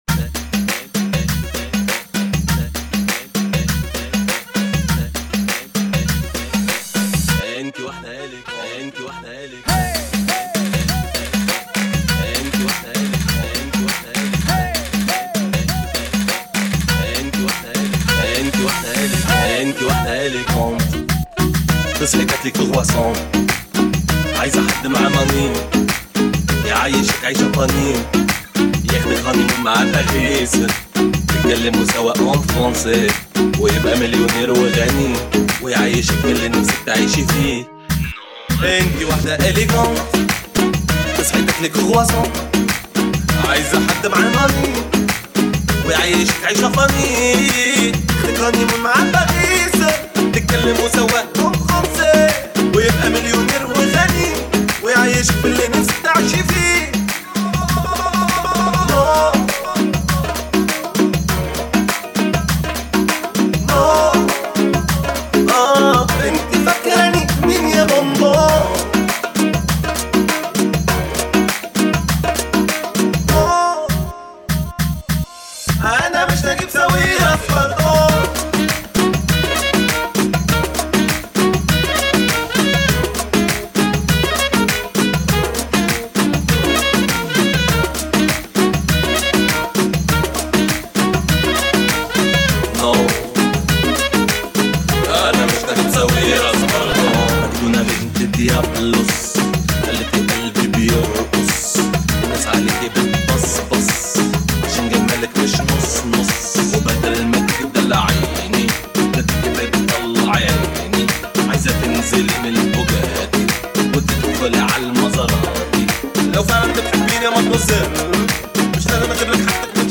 [ 100 bpm ]